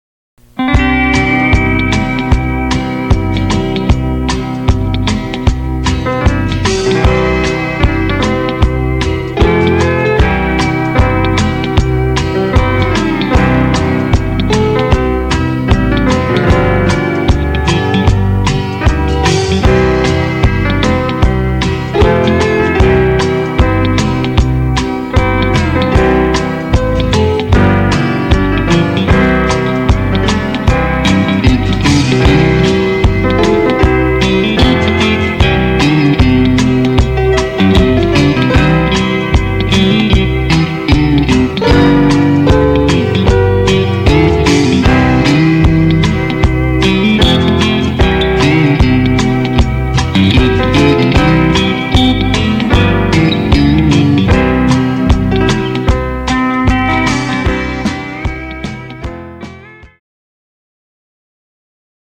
Two Step